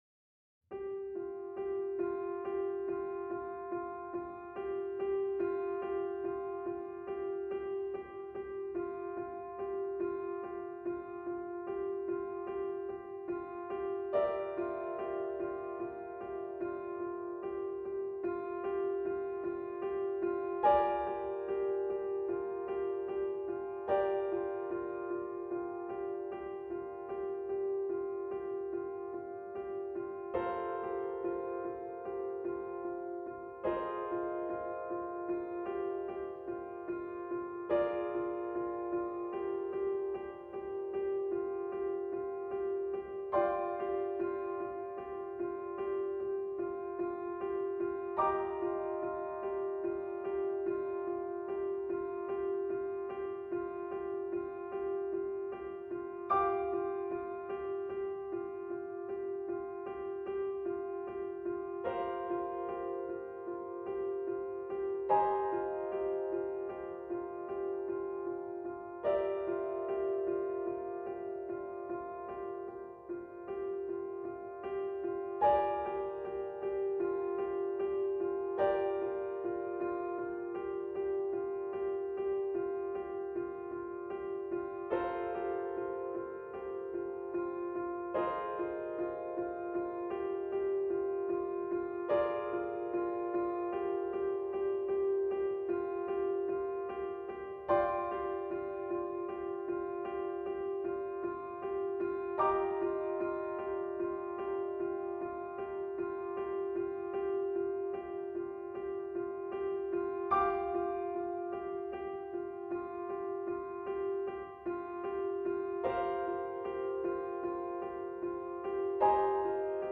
piano Duration